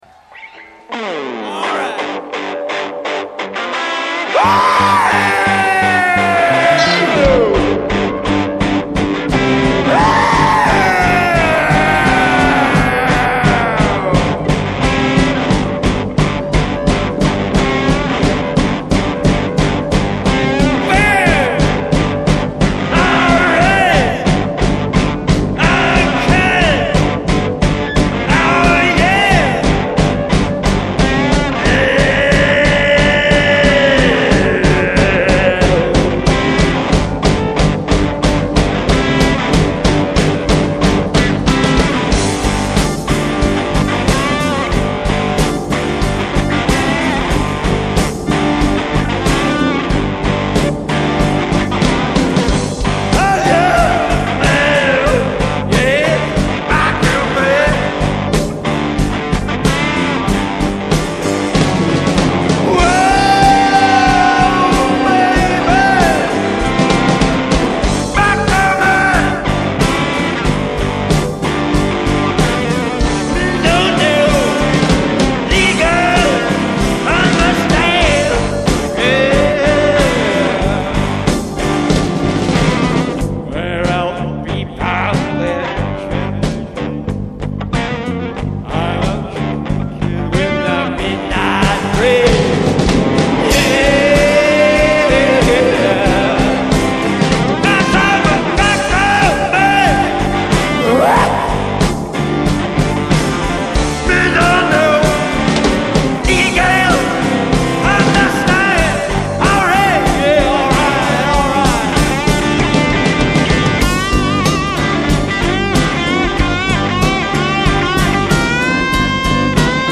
Older mp3s with bass guitar player